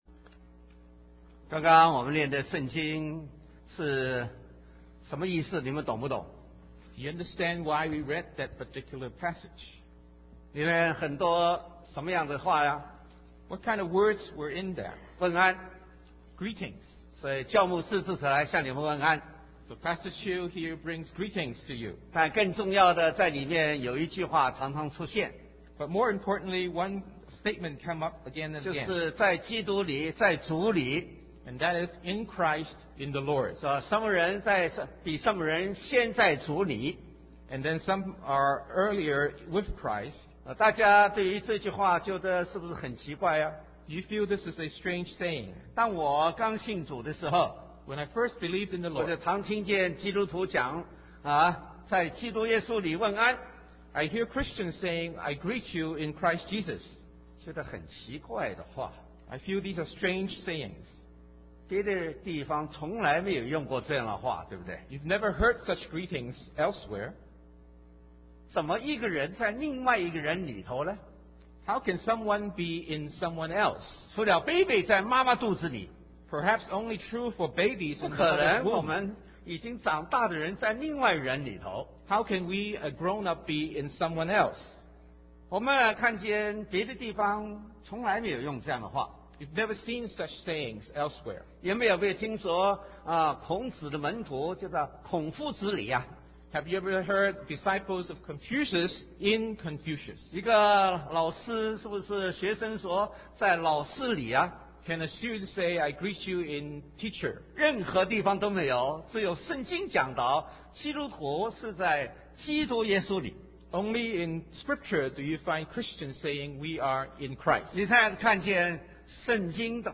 Sermon | CBCGB